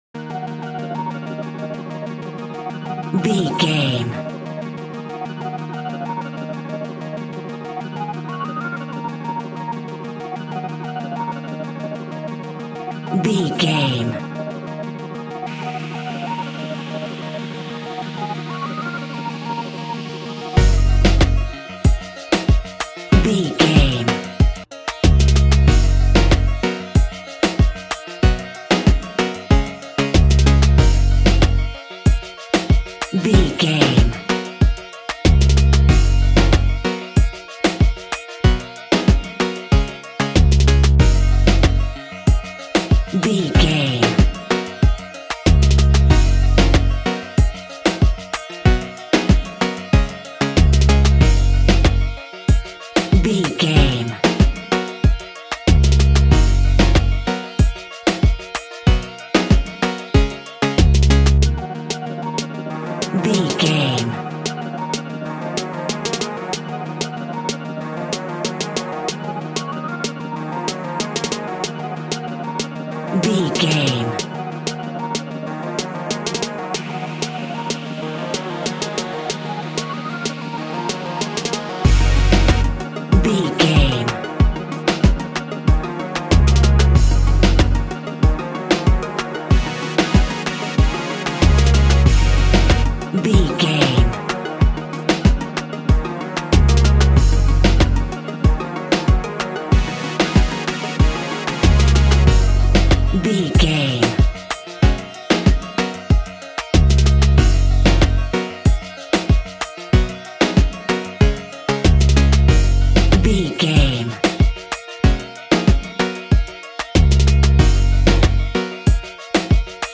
Classic reggae music with that skank bounce reggae feeling.
Ionian/Major
G♭
instrumentals
laid back
chilled
off beat
drums
skank guitar
hammond organ
percussion
horns